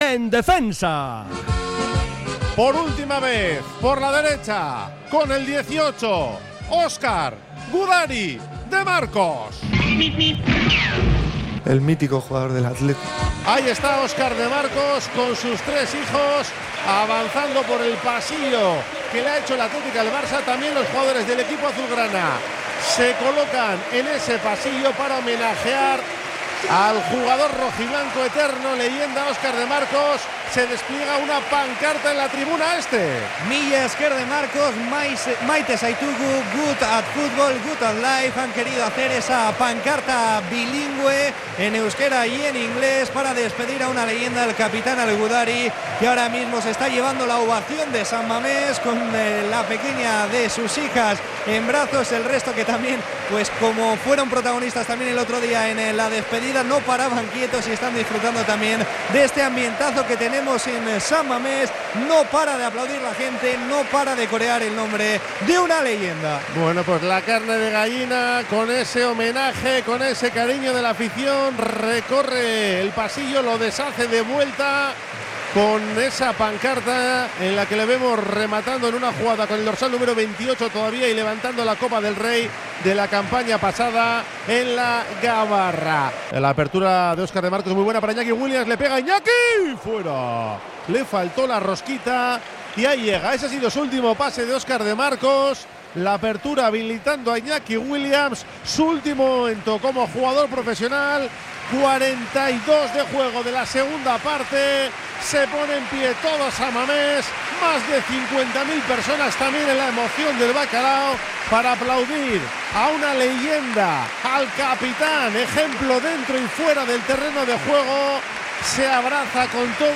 Así vivimos en La Emoción del Bacalao, en Radio Popular – Herri Irratia, la despedida de Óscar de Marcos como jugador del Athletic en San Mamés.